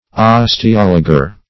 Osteologer \Os`te*ol"o*ger\, n. One versed in osteology; an osteologist.
osteologer.mp3